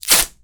LightningShoot.wav